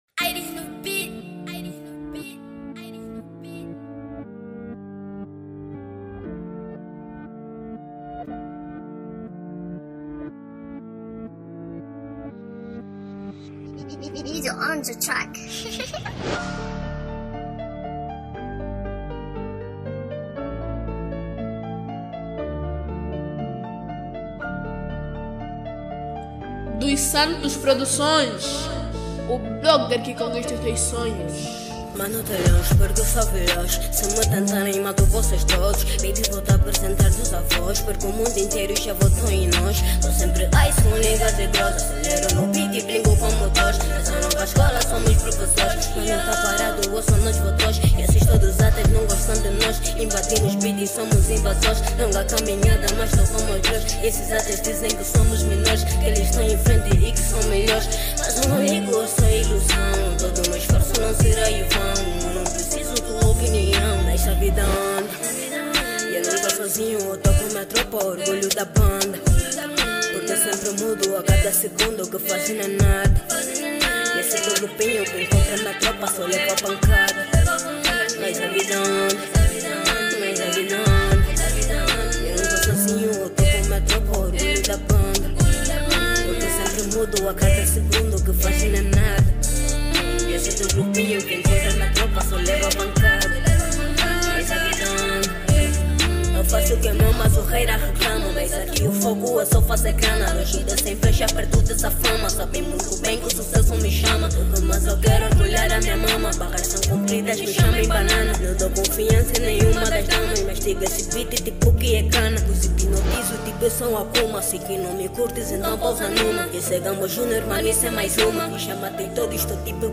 Categoria Trap